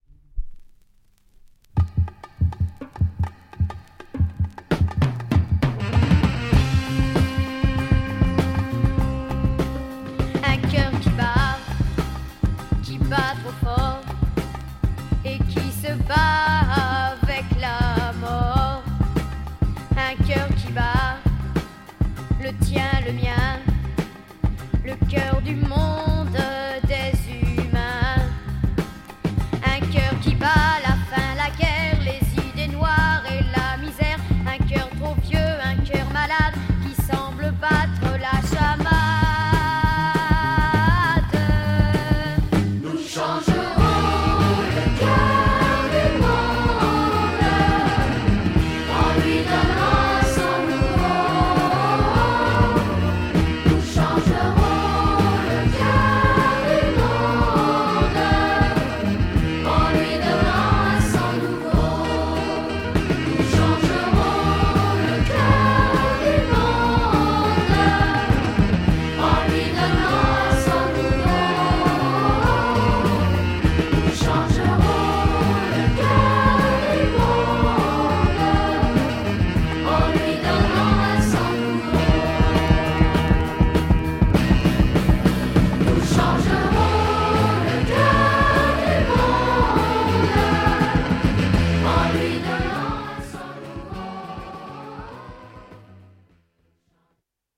with Children vocal, very funny Xian pop psych recording!!